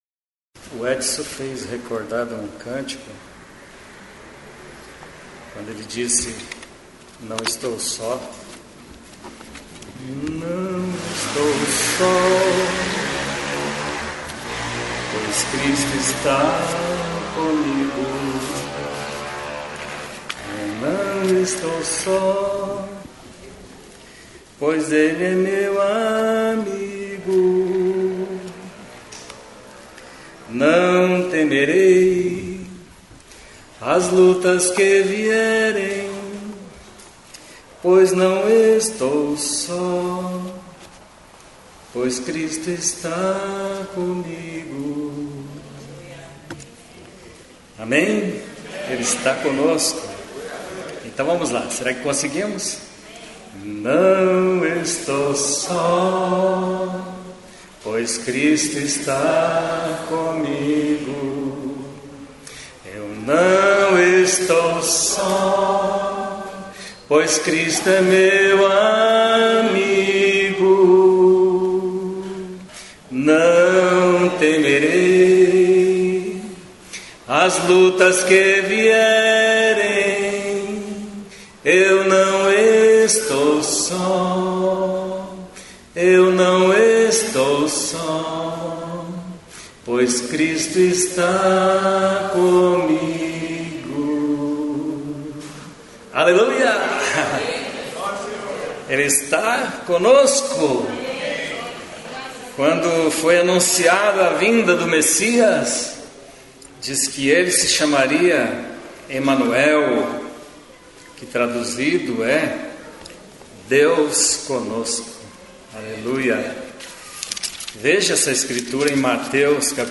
na reunião presencial da igreja em Curitiba no dia 13/03/2022.